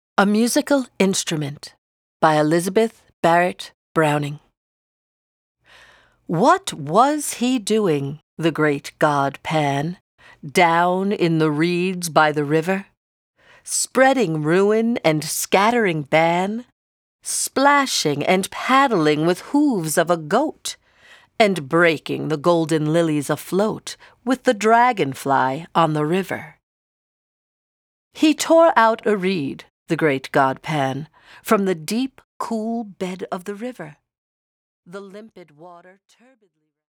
(Narrator)